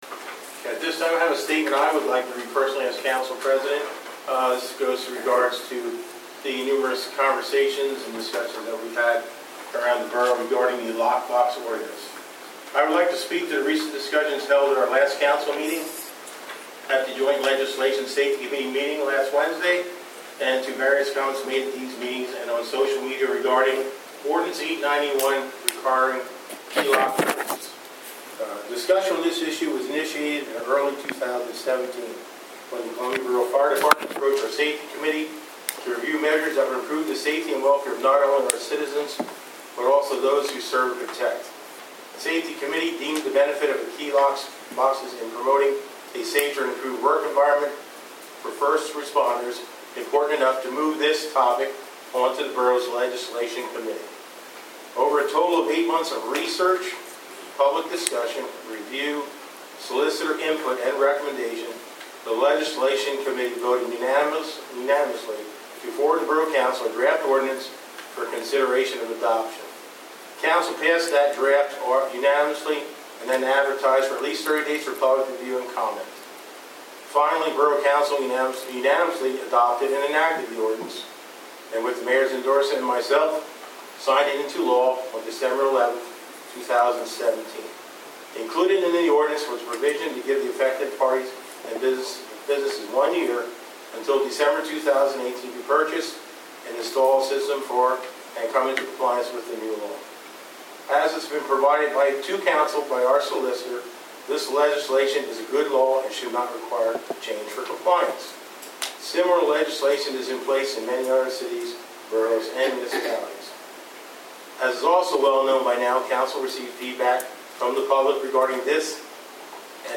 Council president reads statement about borough’s position on lock box ordinance a Monday evening meeting
Near the end of the scheduled agenda topics at Monday night’s Borough Council meeting, Kelly Murphy, council president — in a non-agenda item — read a prepared statement concerning the Lock Box situation that’s been debated at several recent meetings.